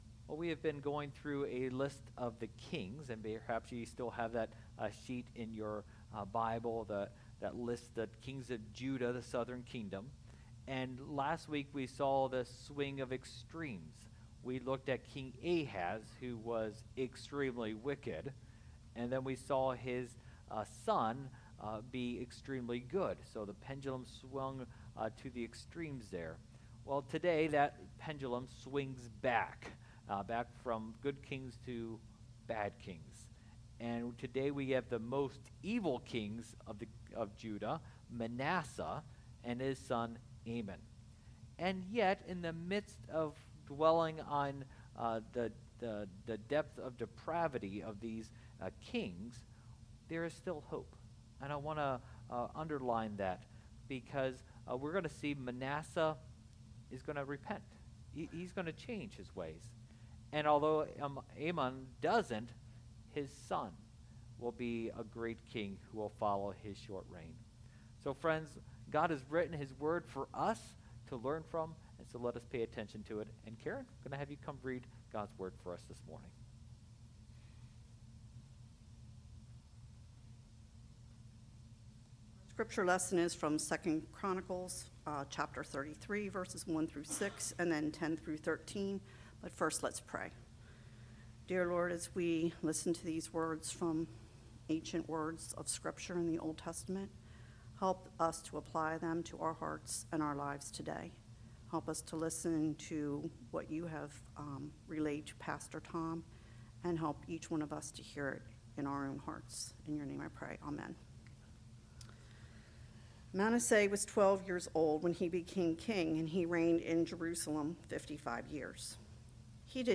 Preacher
Passage: 2 Chronicles 33: 1-6, 10-13 Service Type: Lent